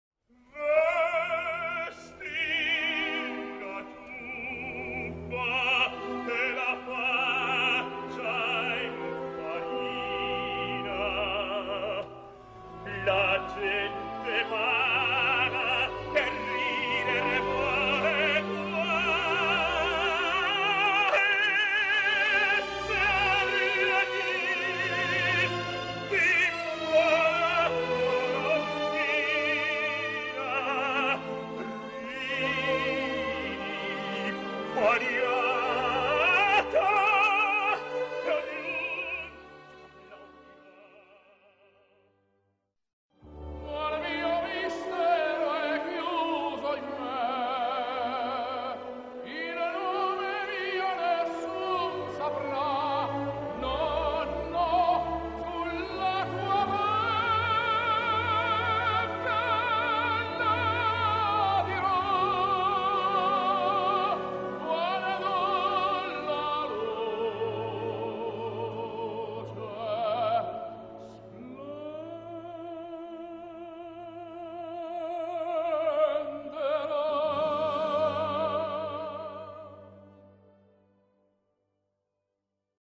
L'ascolto propone due frammenti appartenenti, rispettivamente, a R. Leoncavallo e G. Puccini: (A) Vesti la giubba e (B) Nessun dorma, entrambi per tenore.